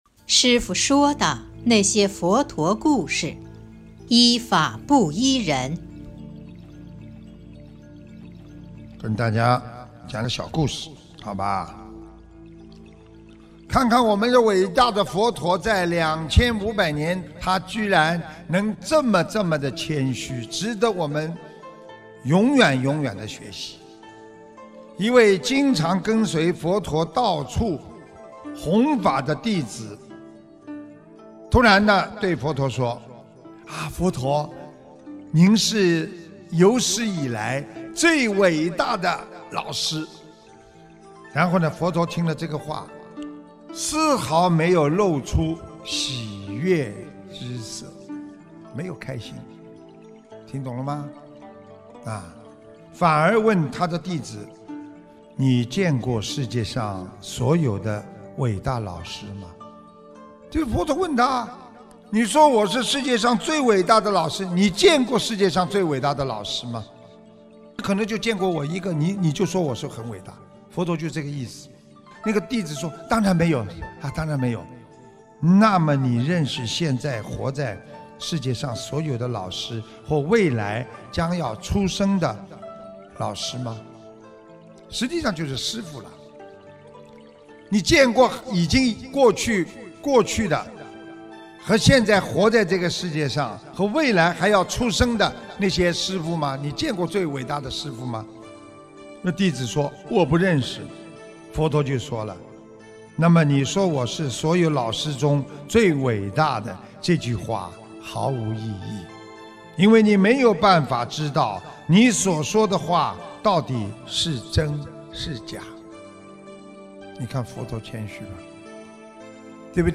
音频：《说不如行、依法不依人》师父说的那些佛陀故事！（后附师父解说）2020年02月26日【师父原声音】